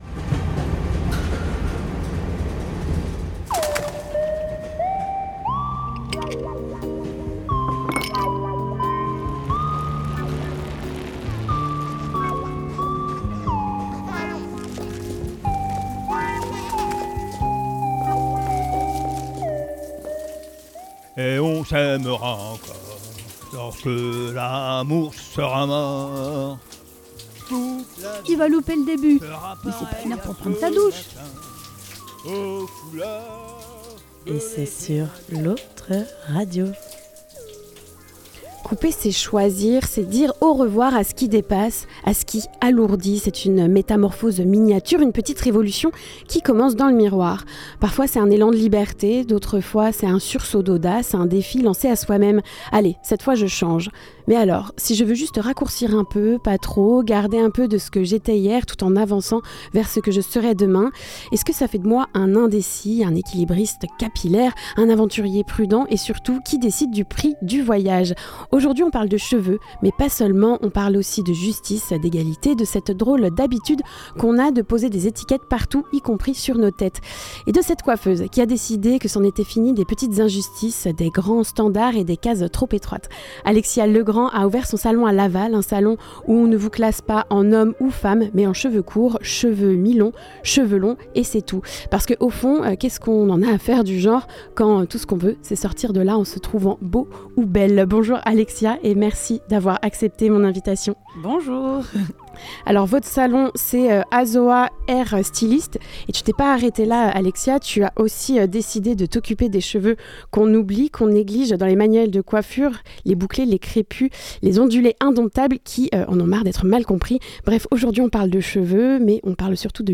On en parle aujourd'hui ensemble.